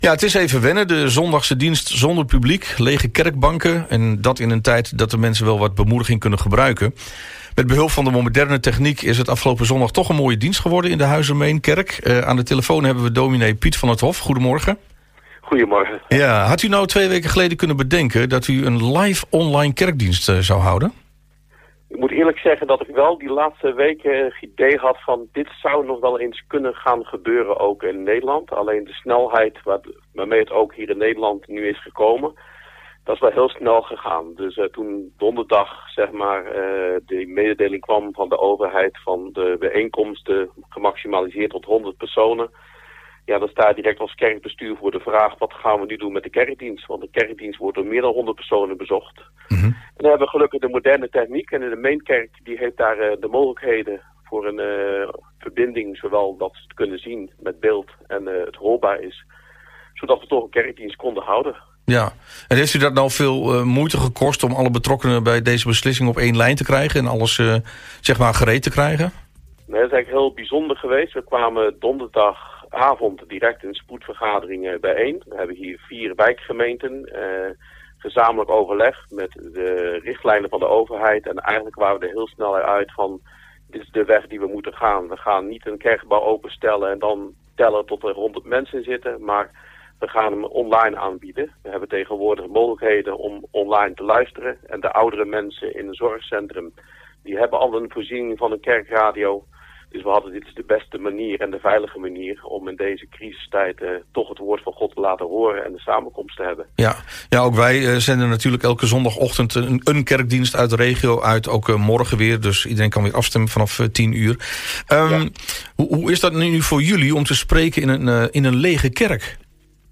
Met behulp van de moderne techniek is het afgelopen zondag toch een mooie dienst geworden in de Huizer Meentkerk. Aan de telefoon